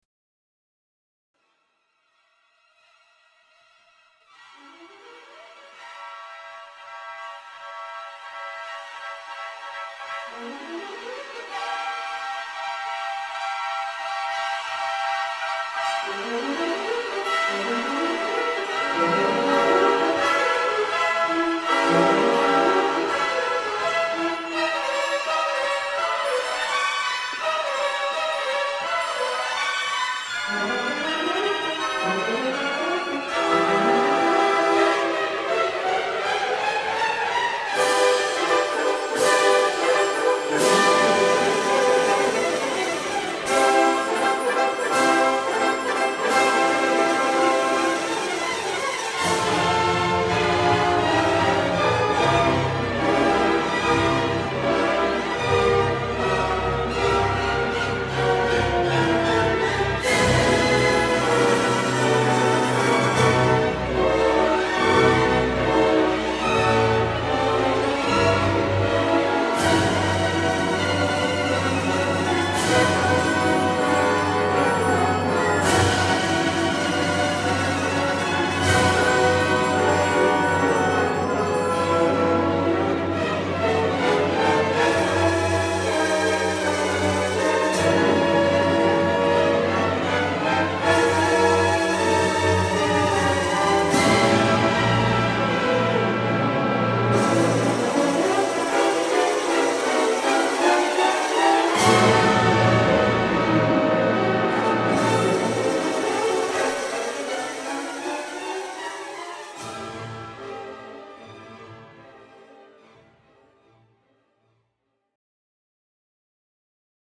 rather daringly steamy opening bacchanale
opera